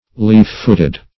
Search Result for " leaf-footed" : The Collaborative International Dictionary of English v.0.48: Leaf-footed \Leaf"-foot`ed\, a. (Zool.)